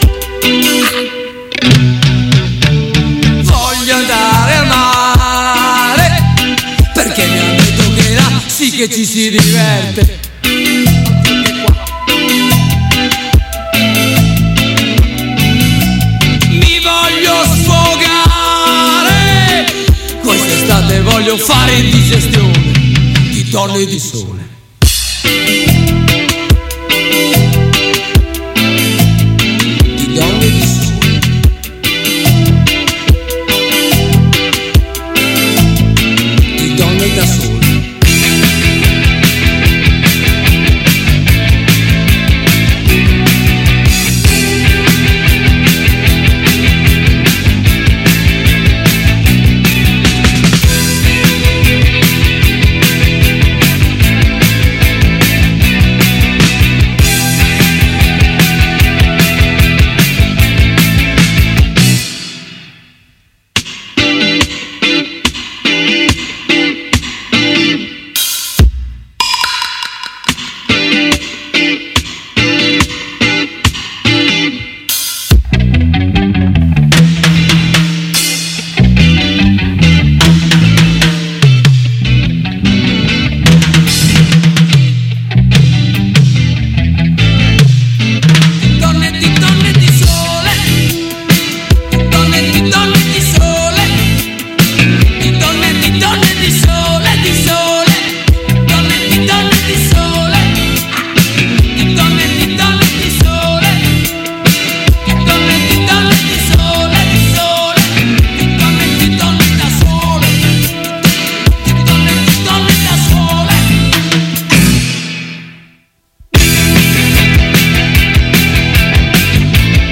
Ultima puntata prima della pausa estiva, Groovy Times vi regala un po’ di musica nuova per rallegrare le vostre vacanze.